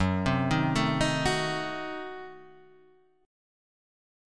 F# Diminished 7th
F#dim7 chord {2 3 1 2 4 2} chord